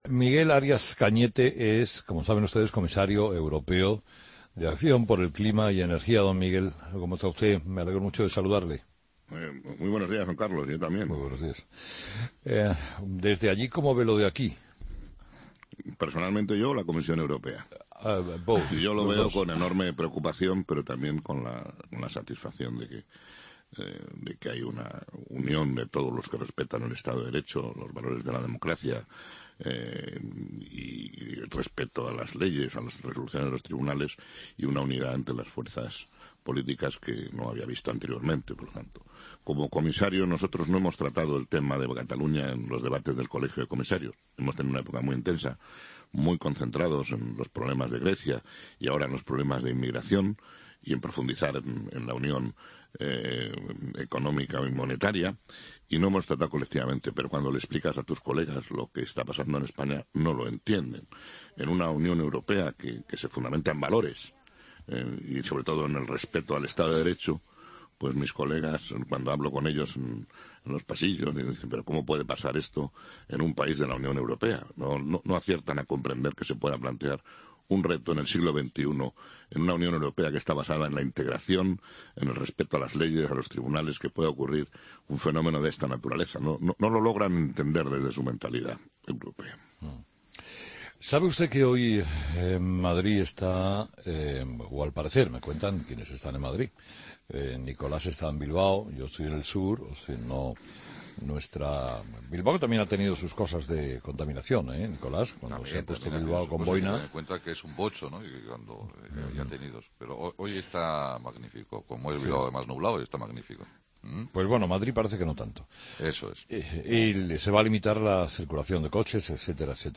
Escucha la entrevista a Miguel Arias Cañete en 'Herrera en COPE'